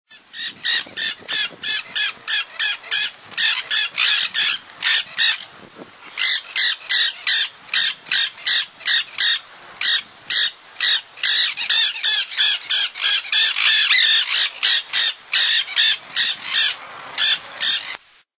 Ñanday (Aratinga nenday)
Nombre en inglés: Nanday Parakeet
Fase de la vida: Adulto
Localidad o área protegida: Reserva Ecológica Costanera Sur (RECS)
Condición: Silvestre
Certeza: Vocalización Grabada